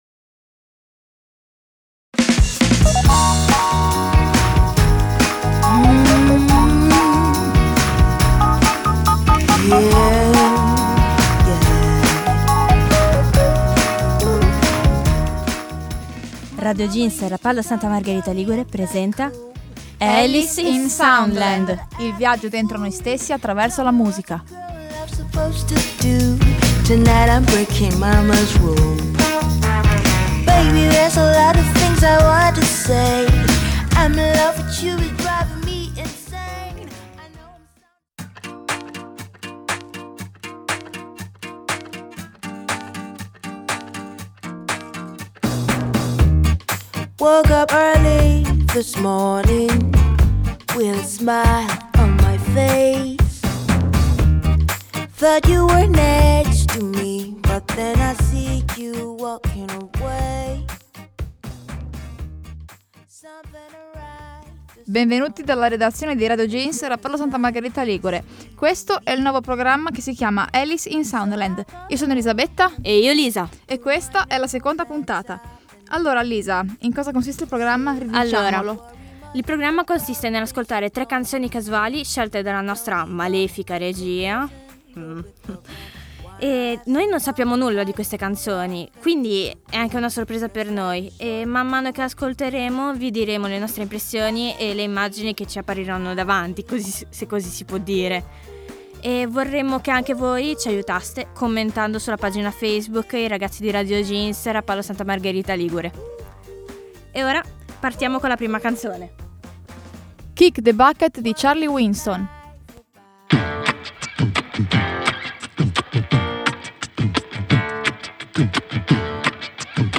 play_circle_filled Alice in Soundland (Puntata 2) Radioweb C.A.G. di Rapallo Seconda puntata del format musicale della redazione di Radio Jeans Rapallo-Santa Margherita Ligure. Tre nuovi brani proposti alle "cavie" nel nostro laboratorio d'ascolto.